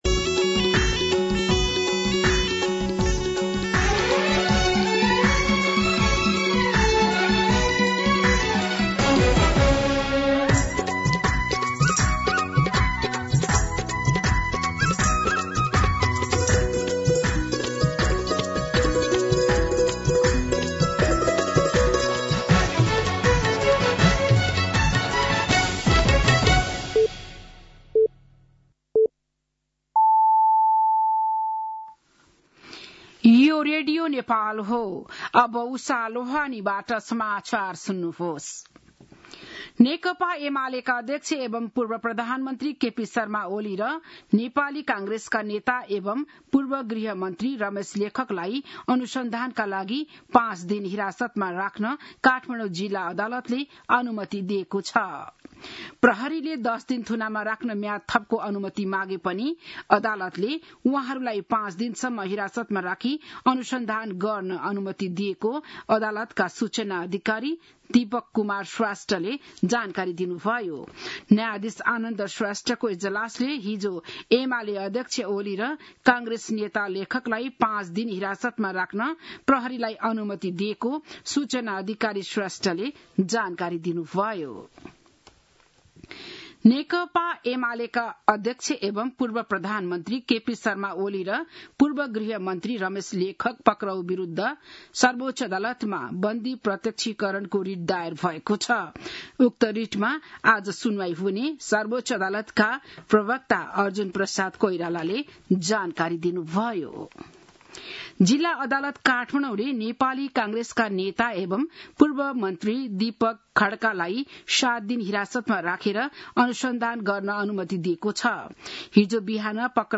बिहान ११ बजेको नेपाली समाचार : १६ चैत , २०८२